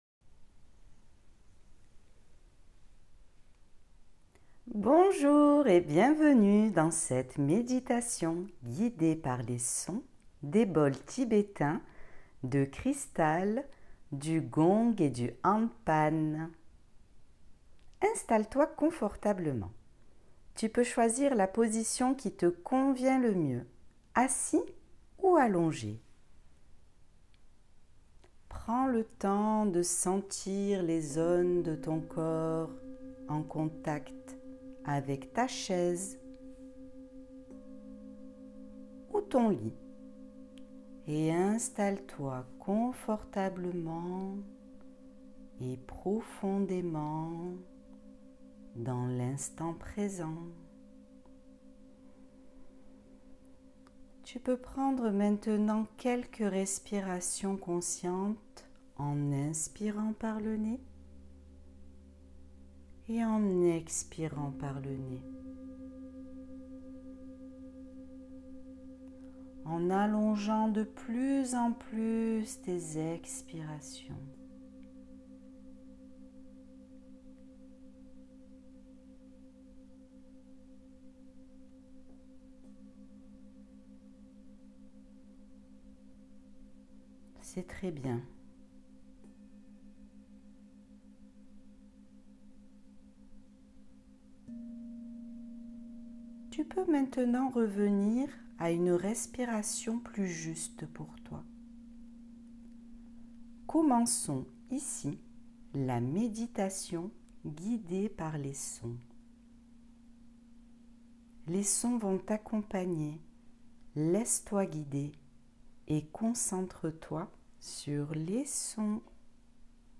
Massage sonore aux bols tibétains, de cristal, gong et diapasons | Méditation sonore.
Relaxation profonde accompagnée par les sons et les vibrations des bols chantants.
J'utilise les bols tibétains, les bols de cristal, les diapasons, les cymbales tibétaines, le gong et le handpan.